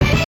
jingles-hit_15.ogg